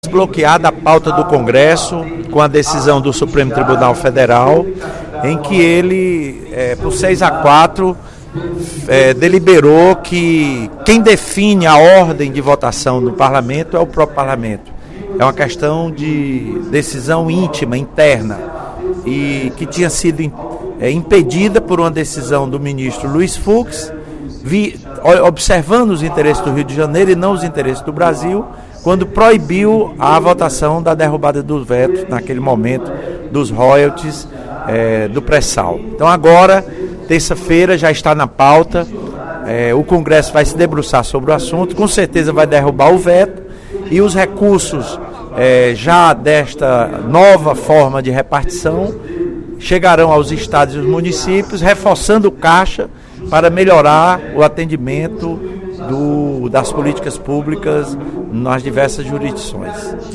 Em pronunciamento durante a sessão plenária desta sexta-feira (01/03), o deputado Lula Morais anunciou o desbloqueio da pauta do Congresso Federal e a votação da distribuição dos royalties do petróleo.